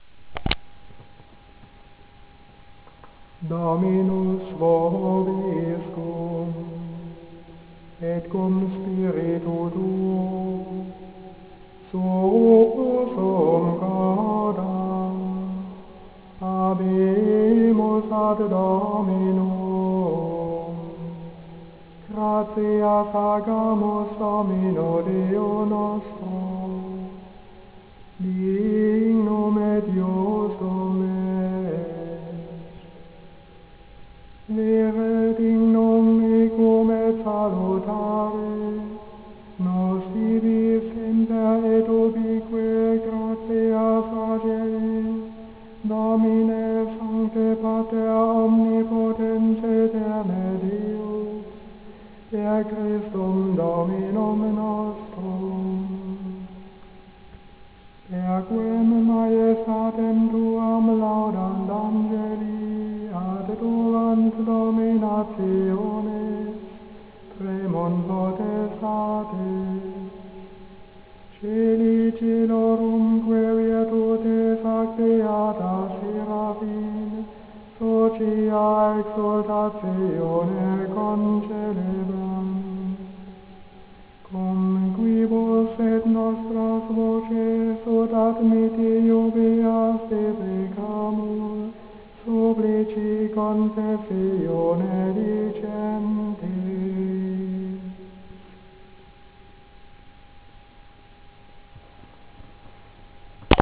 Recordings Cistercian chant